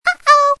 Звуки iCQ
Звук Icq, но немного медленнее